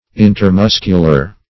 Search Result for " intermuscular" : The Collaborative International Dictionary of English v.0.48: Intermuscular \In`ter*mus"cu*lar\, a. (Anat.) Between muscles; as, intermuscular septa.